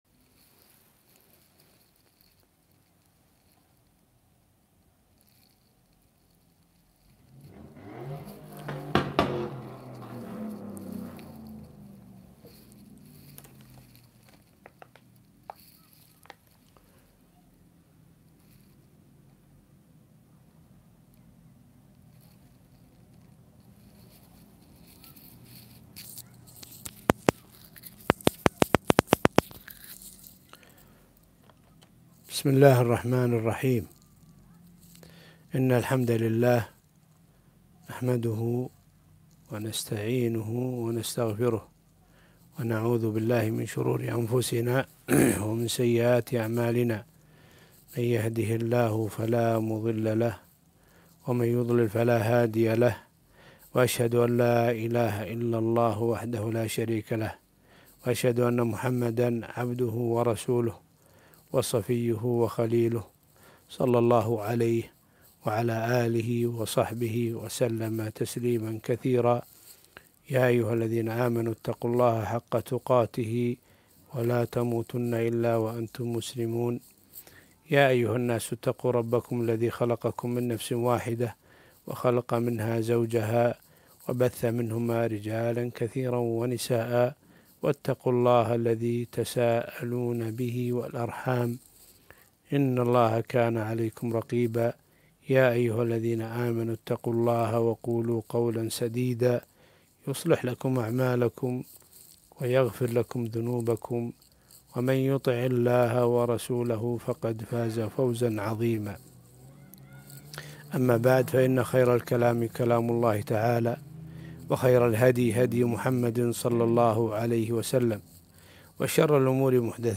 محاضرة - كيف نسعد بشهر رمضان ومواسم الخير